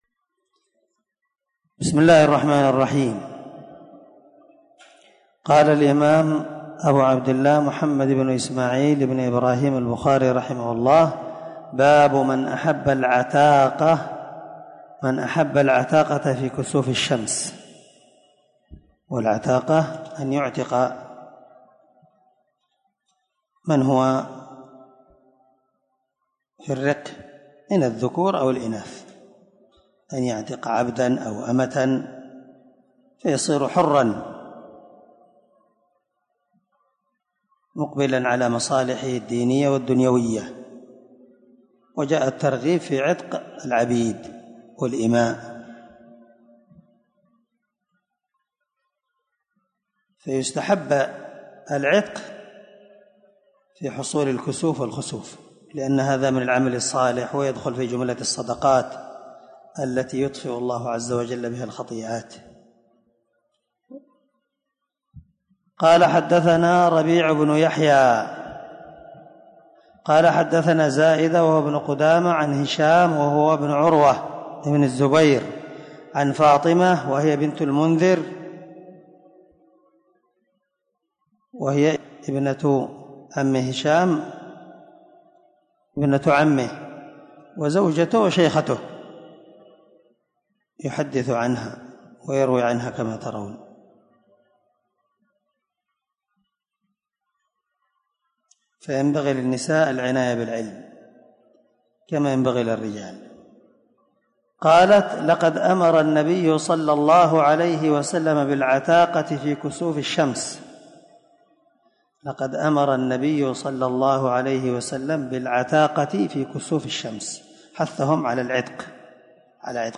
642الدرس 8من شرح كتاب الكسوف حديث رقم(1054-1056) من صحيح البخاري